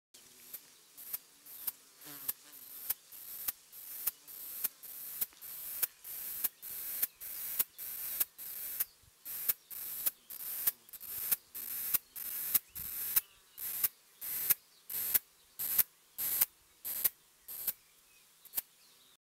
Nous l’avons d’ailleurs appelée la « cigale fredonnante », car elle émet un son à peine audible, très haché et très court.
Enregistrement des cymbalisations du mâle Cicadetta cantilatrix.